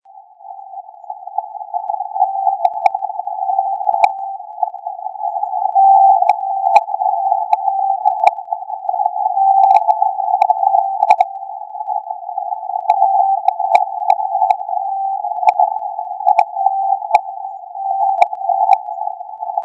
Apart from the strong 18-20Khz stations now are clearly received the RSDN-20 Alpha Primary stations on 11.905,12.649 and 14.881 Khz,
all the four tones where udible! click here for the audio sample